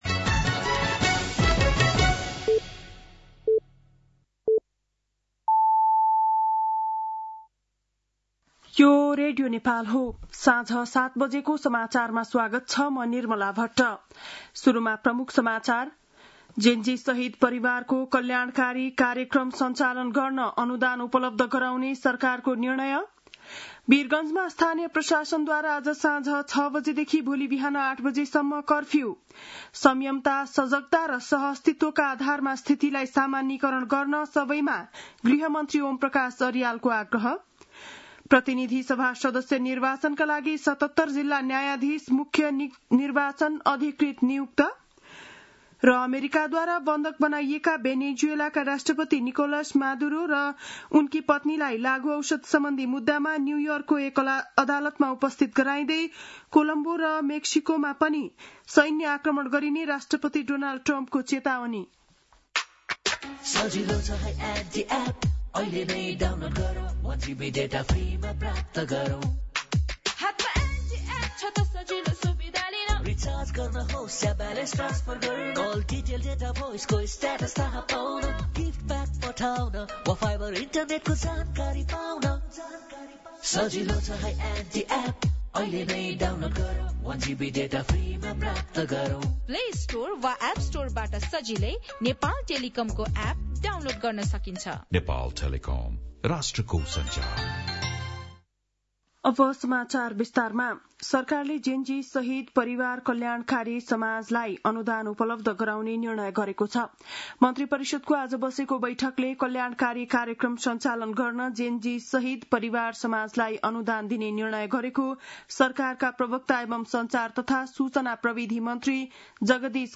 बेलुकी ७ बजेको नेपाली समाचार : २१ पुष , २०८२
7-pm-nepali-news-9-21.mp3